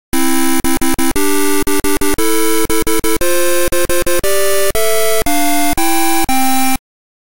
(verze s dvouhlasým přehrávačem a plným tónem)